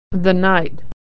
Consonant Sound /th/ - Pattern - The - Authentic American Pronunciation
The article 'the' is also /ðə/in an unstressed (reduced) syllable.
th-night.mp3